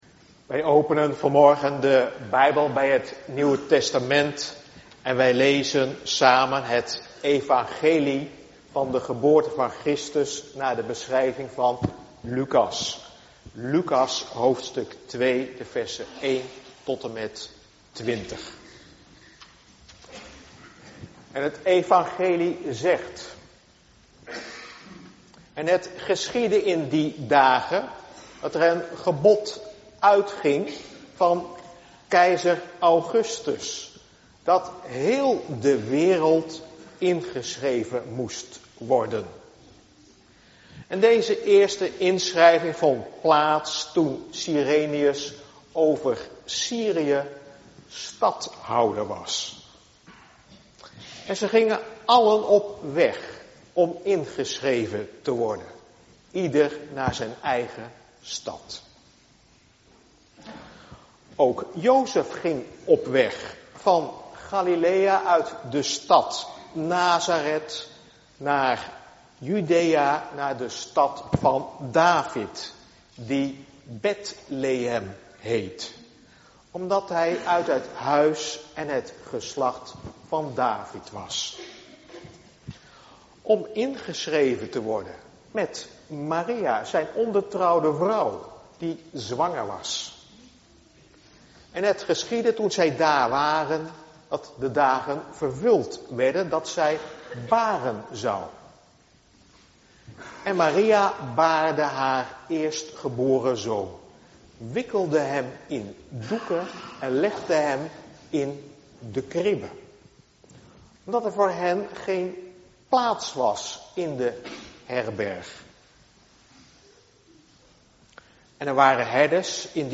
Bekijk verdere details en beluister de preek